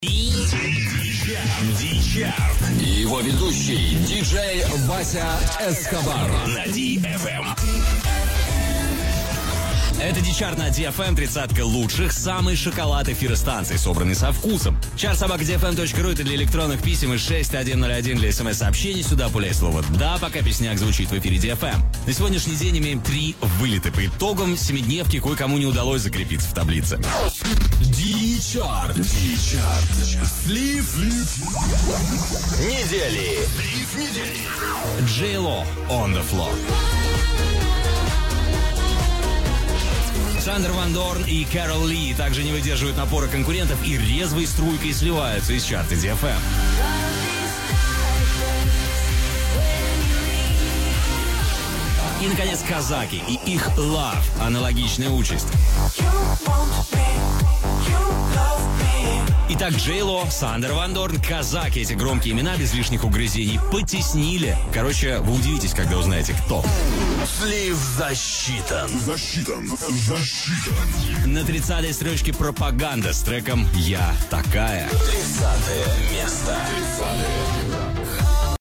Запись эфира.
запись эфира, DFM